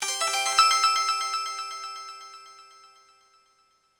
Special & Powerup (12).wav